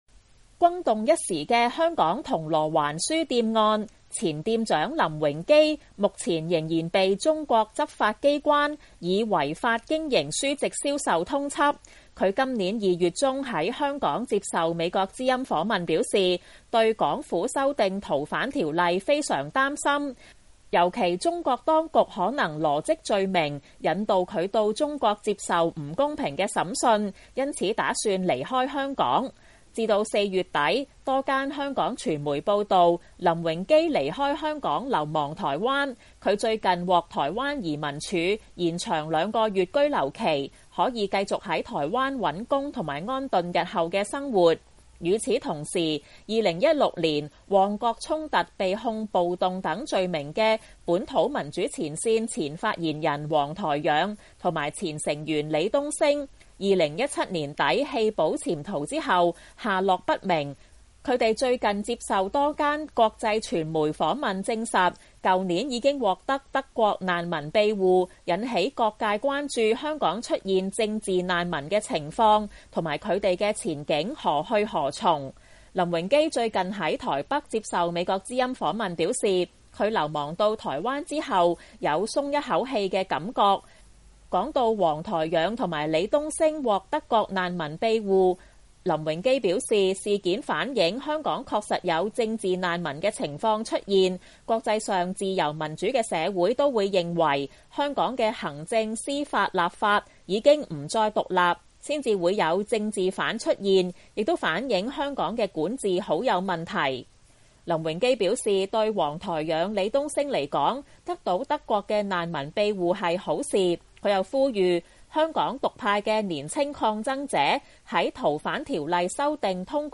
因為憂慮港府修訂《逃犯條例》後會被引渡到中國受審，今年4月底流亡台灣的銅鑼灣書店前店長林榮基接受美國之音訪問表示，台灣雖然未通過難民法，但是有民主自由，無論生活及政治的空間都比香港好得多，他計劃眾籌在台北重開銅鑼灣書店，並呼籲香港獨派年青抗爭者，在7月中逃犯修例可能通過前，盡快考慮離開香港，他又認為台灣是適合香港流亡者生活的地方。